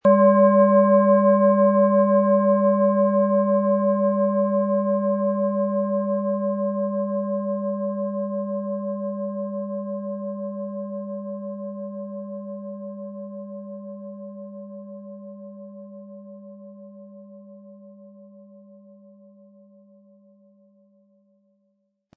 Planetenton 1
Sie sehen eine Planetenklangschale Merkur, die in alter Tradition aus Bronze von Hand getrieben worden ist.
Spielen Sie die Schale mit dem kostenfrei beigelegten Klöppel sanft an und sie wird wohltuend erklingen.
MaterialBronze